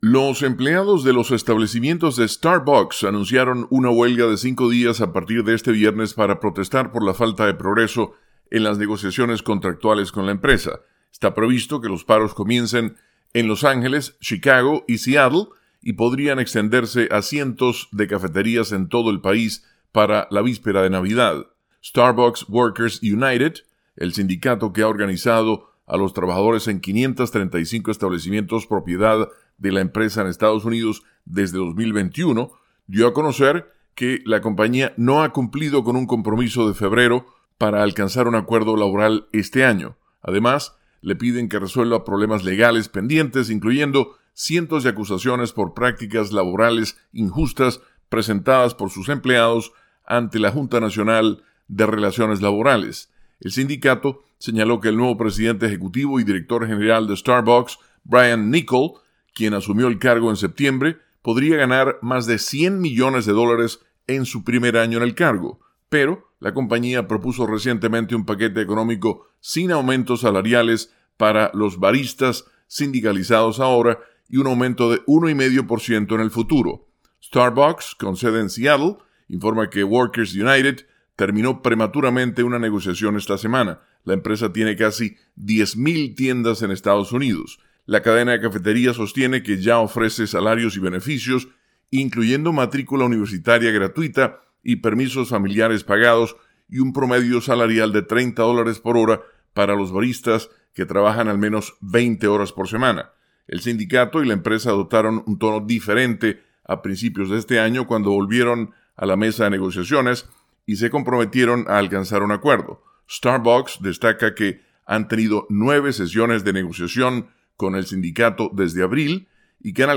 desde la Voz de América, en Washington.